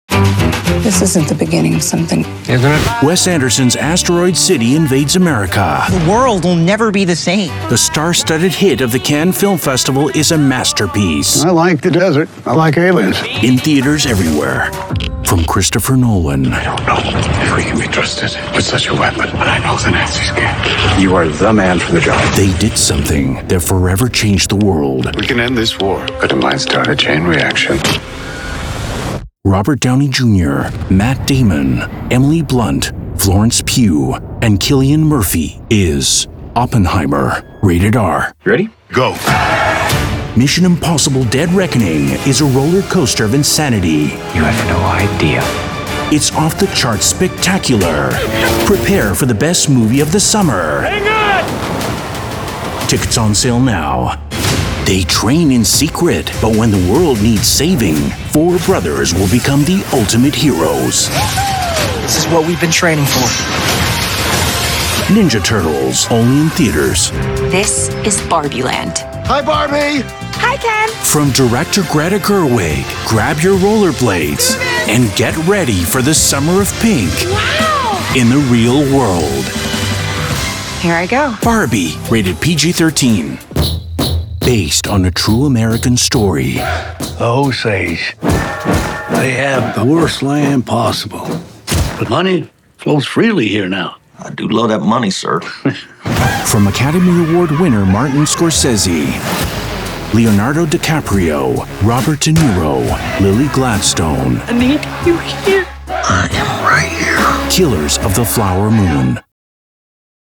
Trailer Demo
Middle Aged
My broadcast quality studio includes an acoustically treated iso-booth and industry standard equipment including:
-Sennheiser MKH-416 mic